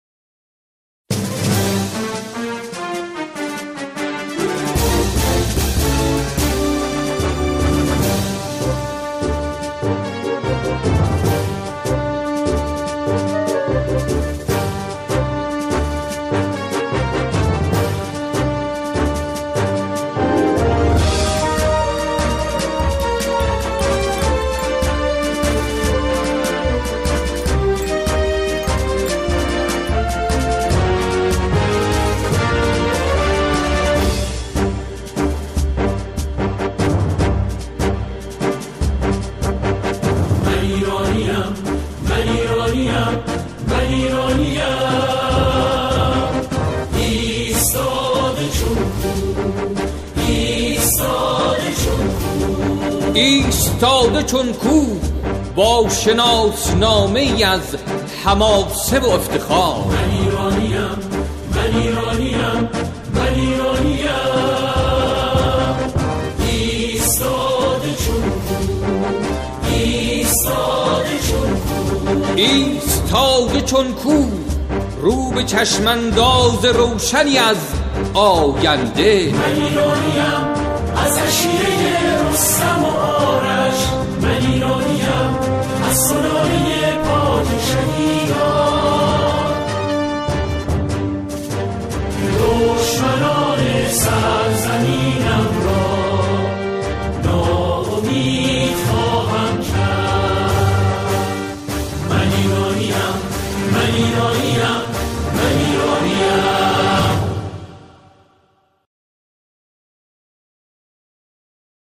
گروهی از همخوانان اجرا می‌کنند